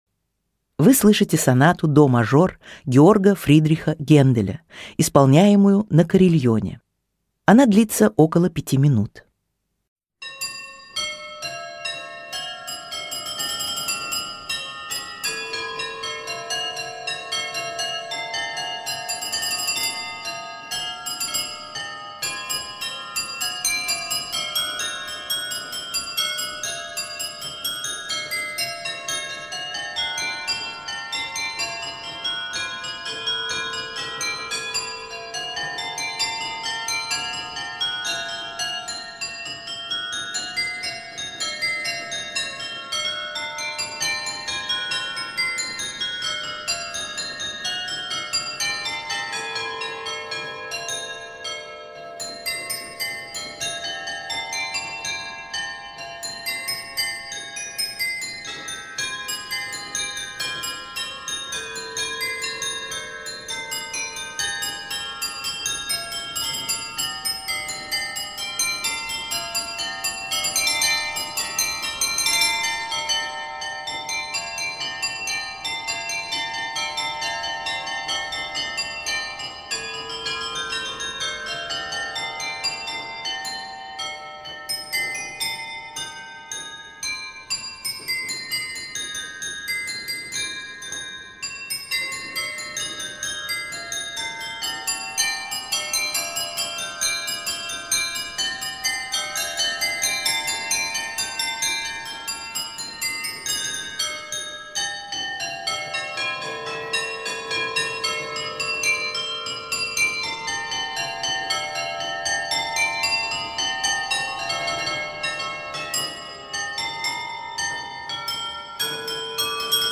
Карильон
Фарфор, дерево, металл